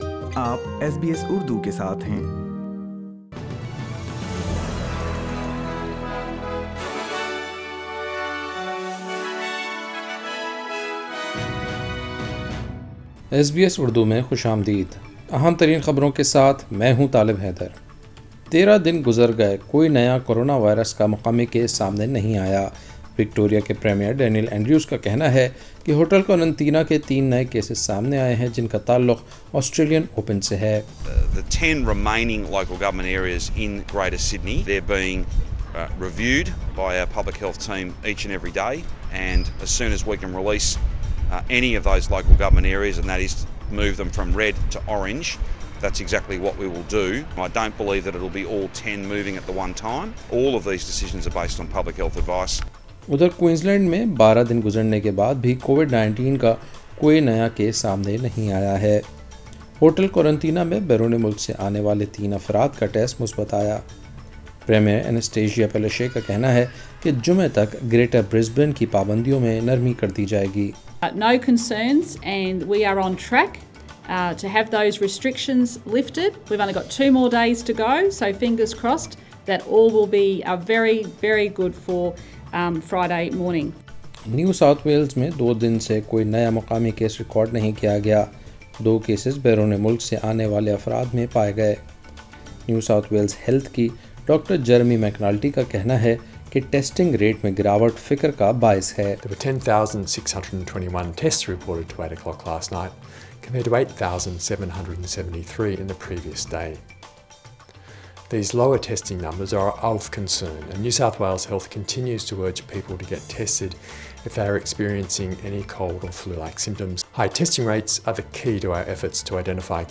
ایس بی ایس اردو خبریں 19 جنوری 2021